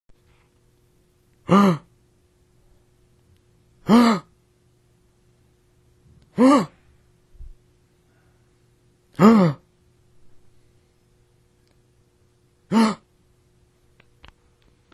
小团体的喘息声（清洁前清洁后）
声道立体声